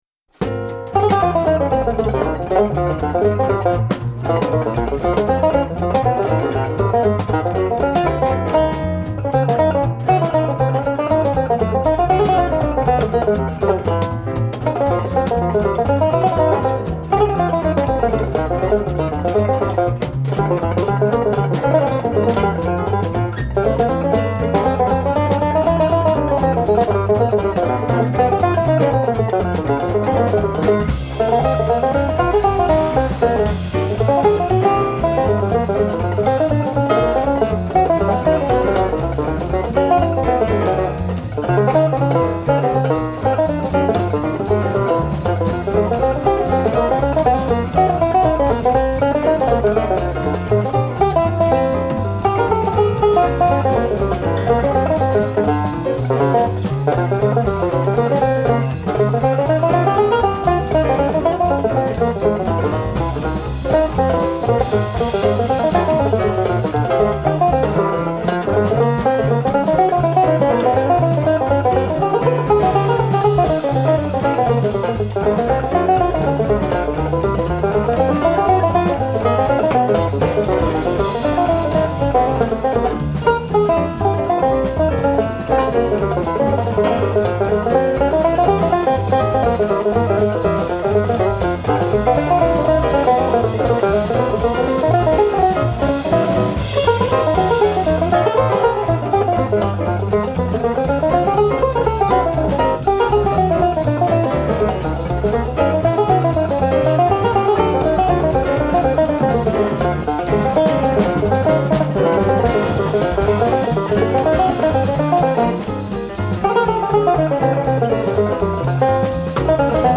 Jazz Banjo
Hold onto your hats. This is real Bebop Banjo.